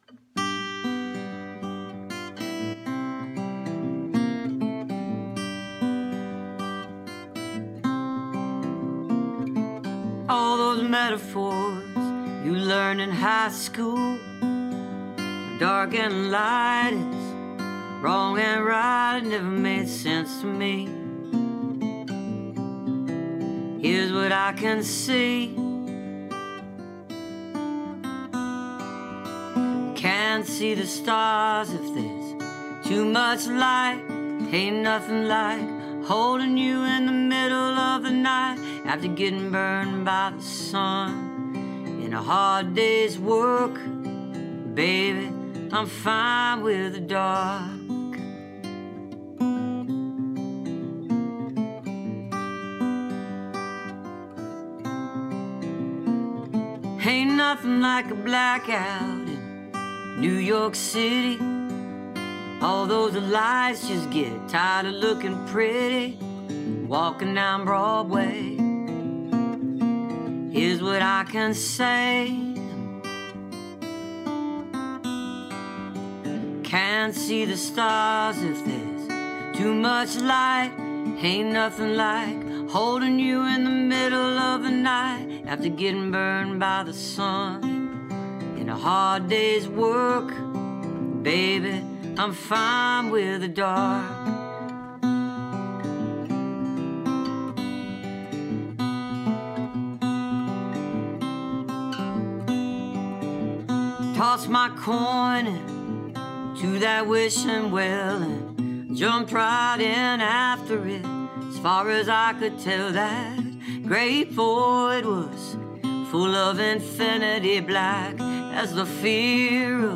(captured from the youtube video stream)